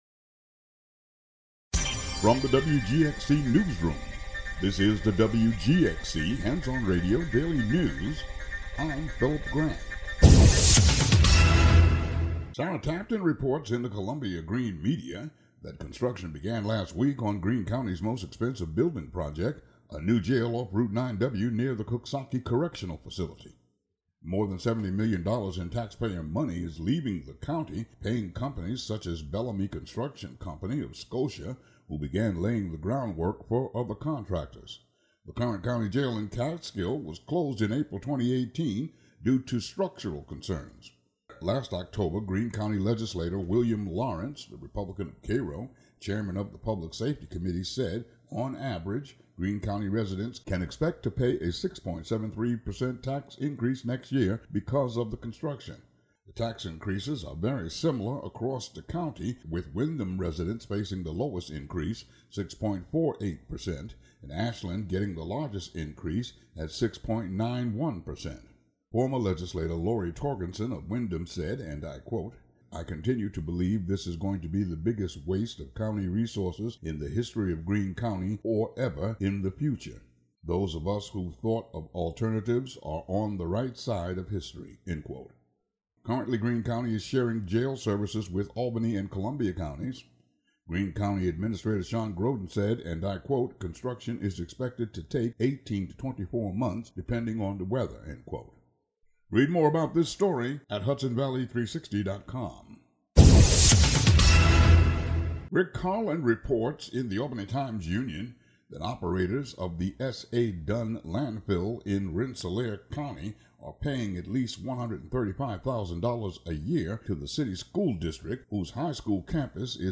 Uncategorized Local headlines and weather